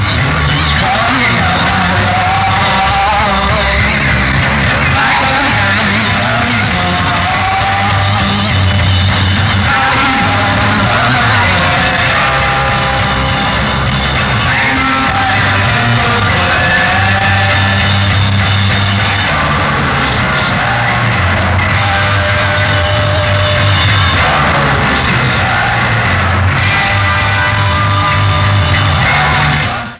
Format/Rating/Source: CD - D - Audience
Comments: Rare concert material, very poor sound quality.
Rochester '67
* Compression added to enhance the sound quality